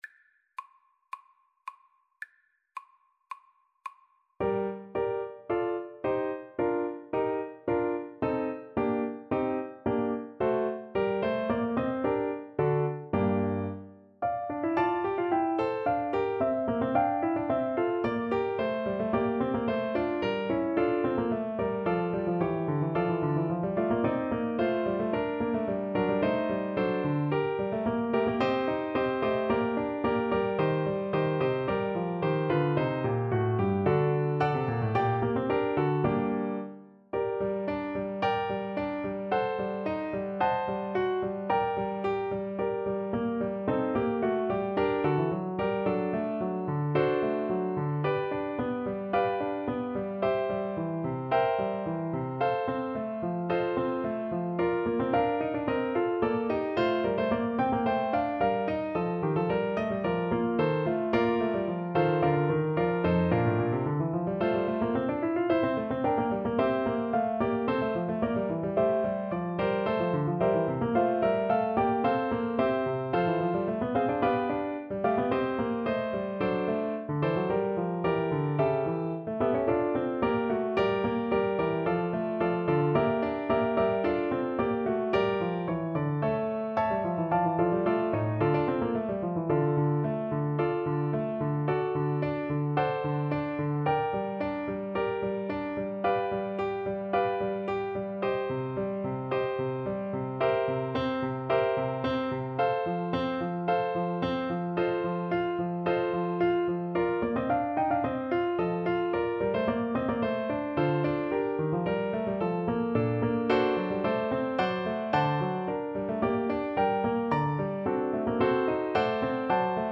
2/2 (View more 2/2 Music)
G minor (Sounding Pitch) (View more G minor Music for Bassoon )
Allegro =110 (View more music marked Allegro)
Classical (View more Classical Bassoon Music)